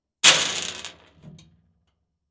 crack.wav